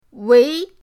wei2.mp3